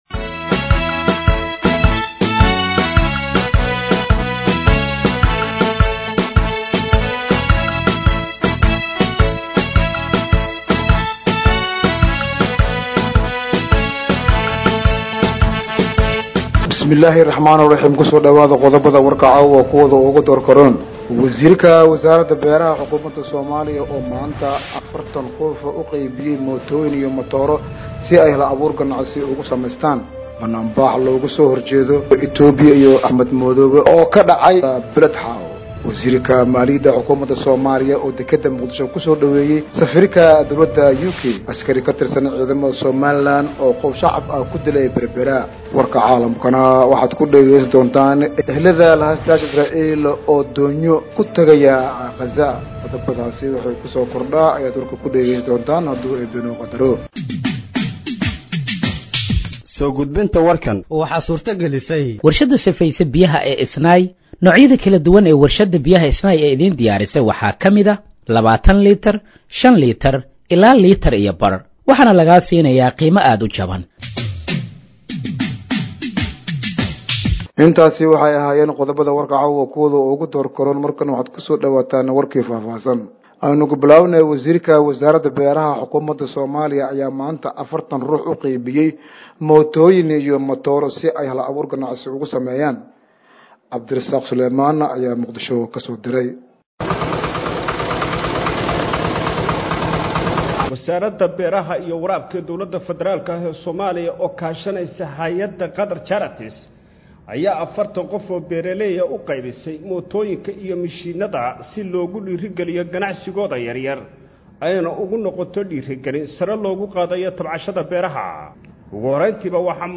Dhageeyso Warka Habeenimo ee Radiojowhar 07/08/2025